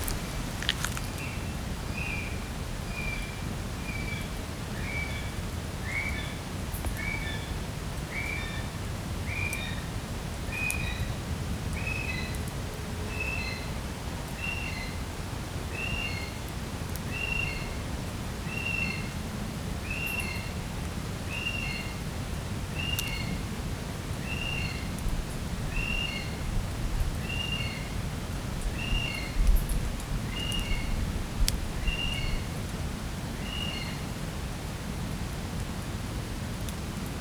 북섬 브라운키위 ''Apteryx mantelli'' 수컷의 울음소리